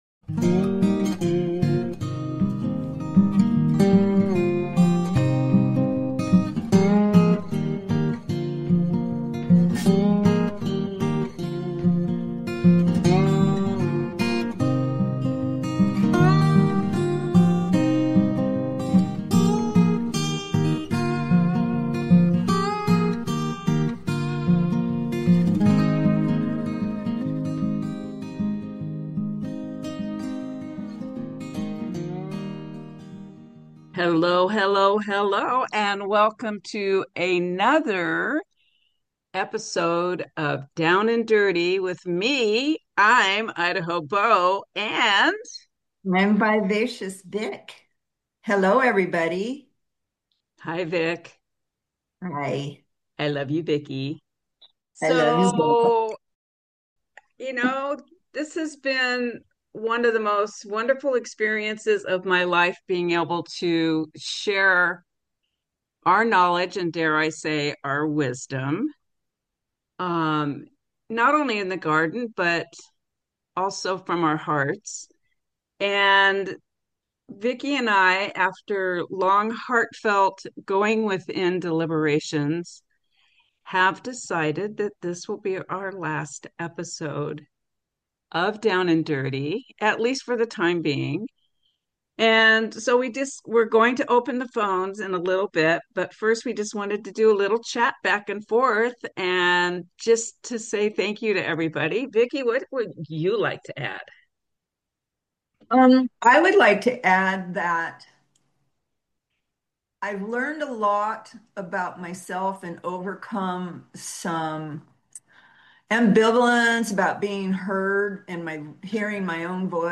Talk Show Episode
Expect lively chats with guest experts and answers to the big question: Got land?